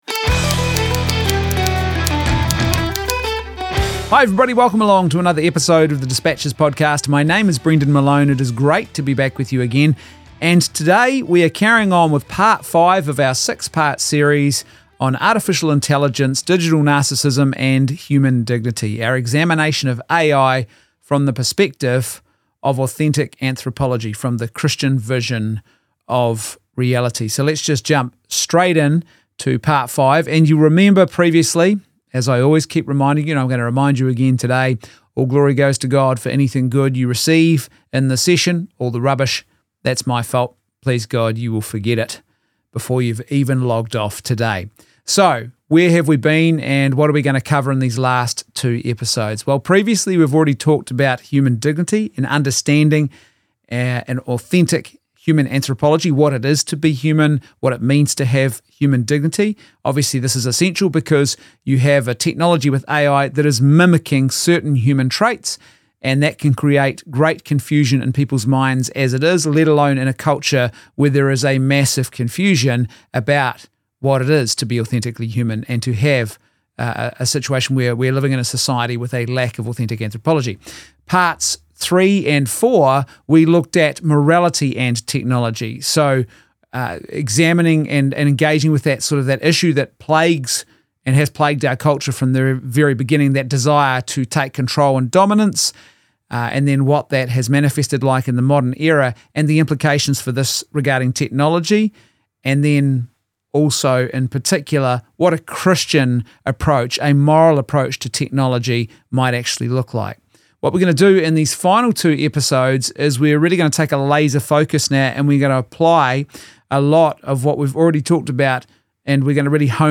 The fifth episode in a special 6 part podcast adaption of a lecture series that I was asked to present at an event in January 2026 on Artificial Intelligence in the light of Christian anthropology. In this episode we focus on the backstory of AI; some key facts and definitions about AI; and some examples of morally good uses of AI that contribute to human flourishing.